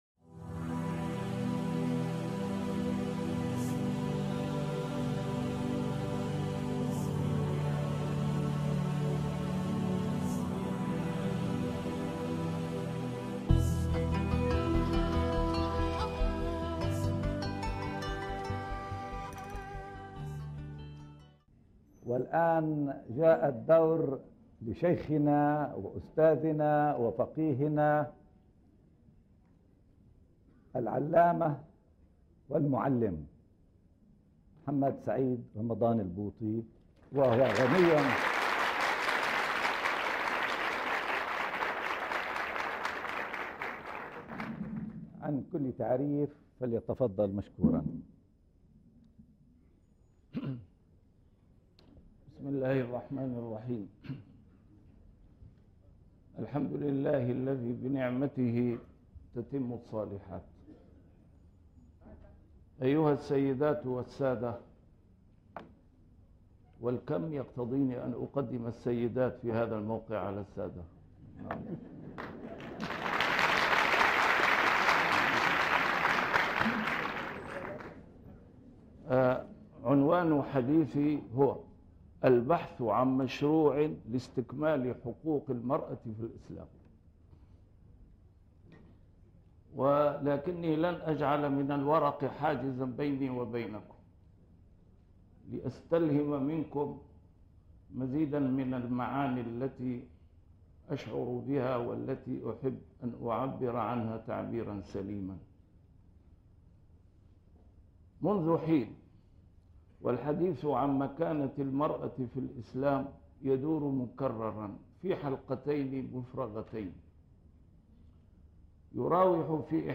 A MARTYR SCHOLAR: IMAM MUHAMMAD SAEED RAMADAN AL-BOUTI - الدروس العلمية - محاضرات متفرقة في مناسبات مختلفة - ندوة المرأة بين الفكر الديني والفلسفي